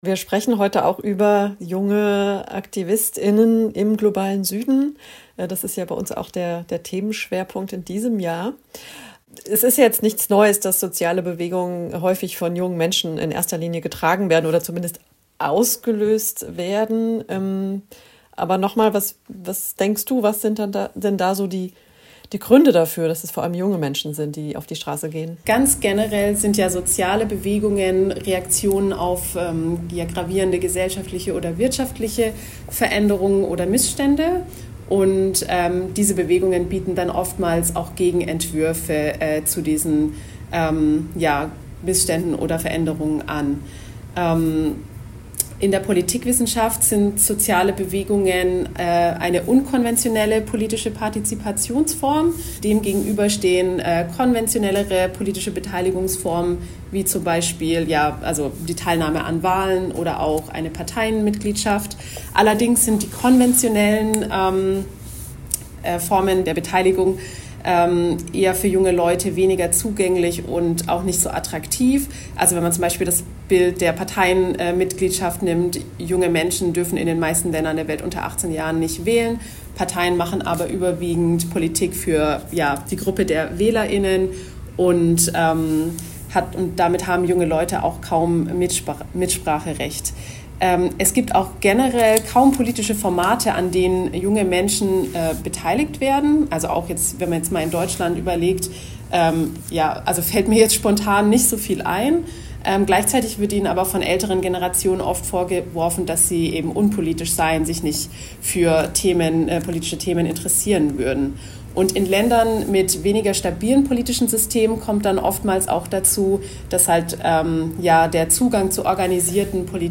Rede & Aufruf Feministische Aktion 8. März: 7:14
Grußworte Feminism Unstoppable München: 0:53